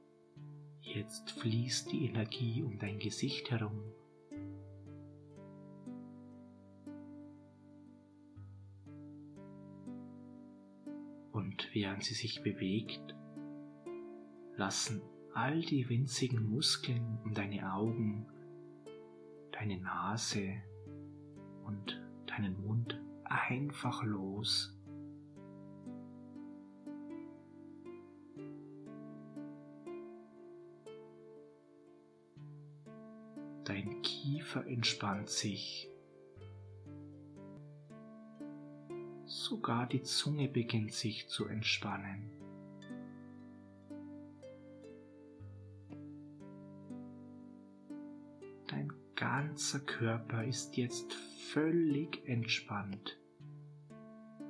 Sie erhalten mit diesem Paket zwei geführte Hypnosen: